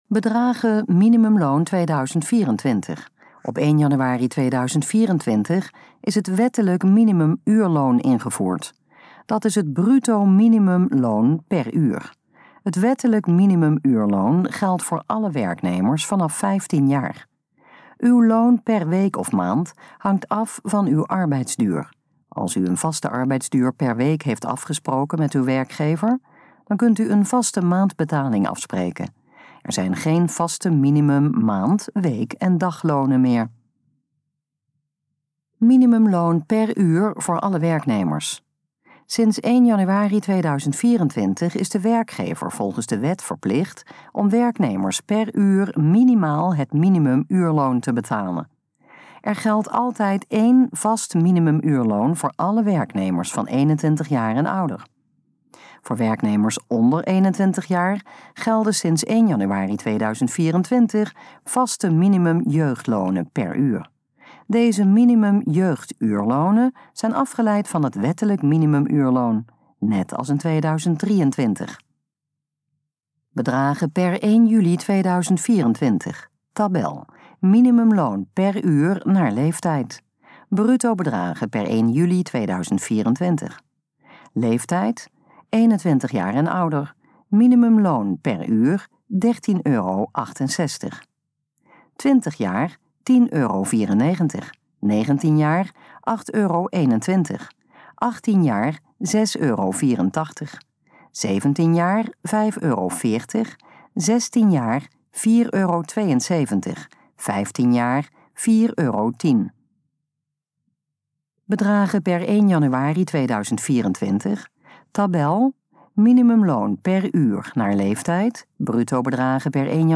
Gesproken versie van Bedragen minimumloon 2024
In dit geluidsfragment hoort u infomatie over de bruto bedragen voor het minimumloon 2024. Het fragment is de gesproken versie van de informatie op de pagina Bedragen minimumloon 2024.